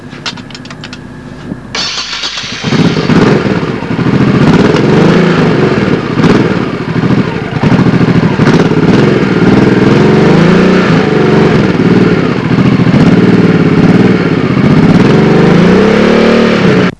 Vous allez trouver tous les sons de bécanes ici, ça va du bruit d'échappement quelconque aux moteurs de sportives en furie, je vous laisse découvrir...
1000 Shadow Honda